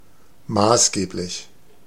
Ääntäminen
IPA : /ɪˈsɛn.ʃəl/